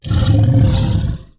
ANIMAL_Tiger_Growl_03.wav